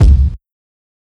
KICK_TWISTED.wav